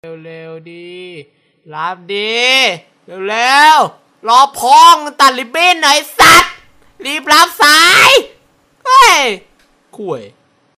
ริงโทนที่กวนส้นตีนที่สุด
หมวดหมู่: เสียงเรียกเข้า
nhac-chuong-treu-dua-buon-cuoi-nhat-th-www_tiengdong_com.mp3